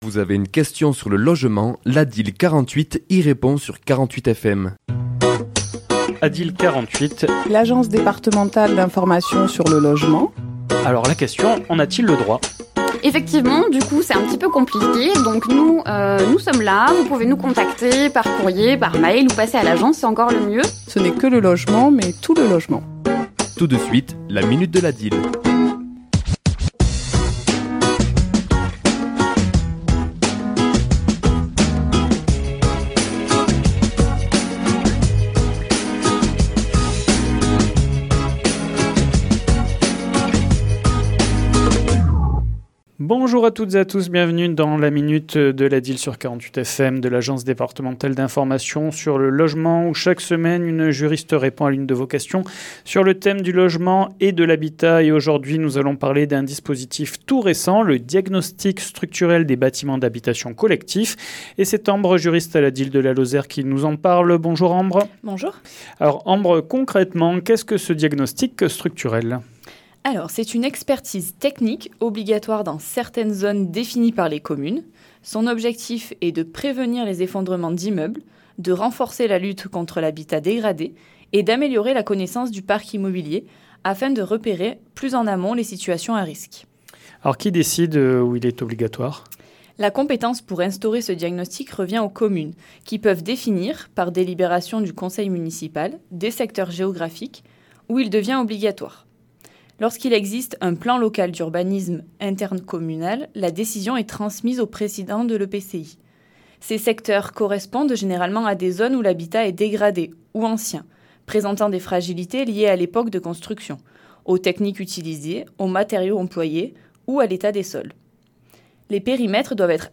Chronique diffusée le mardi 14 octobre à 11h et 17h10